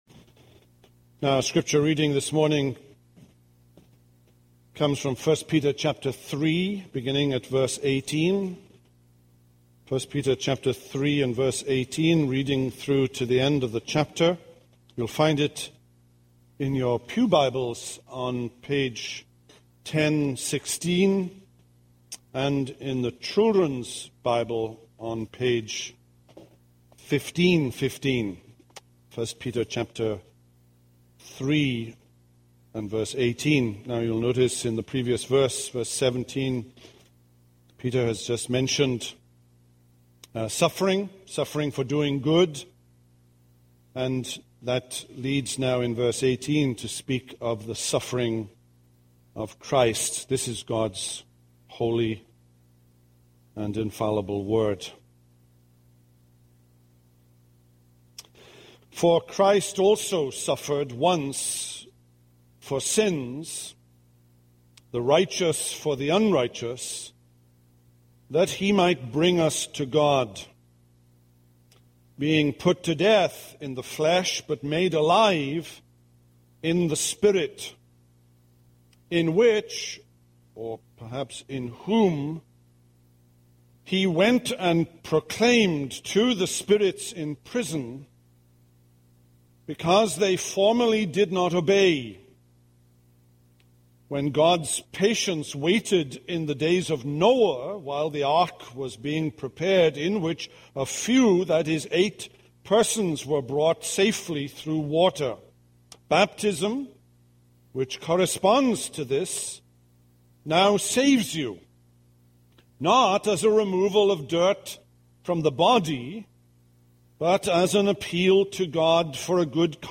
This is a sermon on 1 Peter 3:18-22.